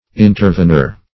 intervenor - definition of intervenor - synonyms, pronunciation, spelling from Free Dictionary